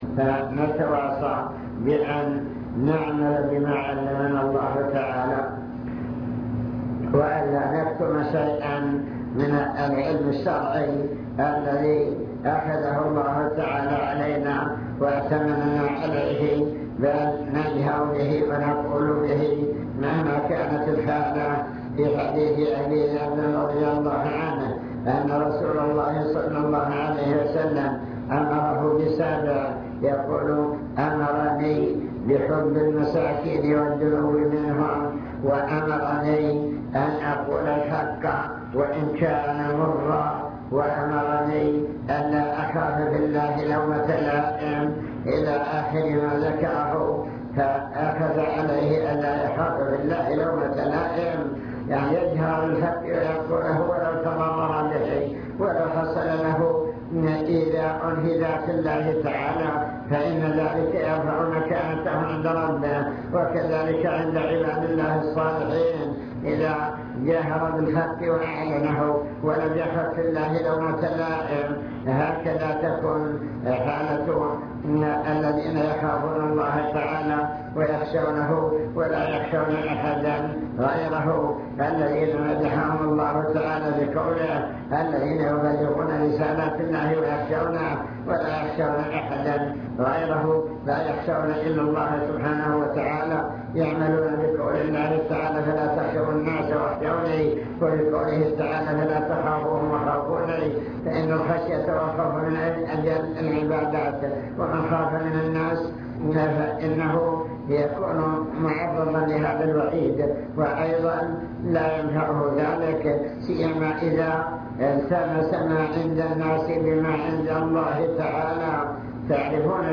المكتبة الصوتية  تسجيلات - لقاءات  لقاء إدارة التعليم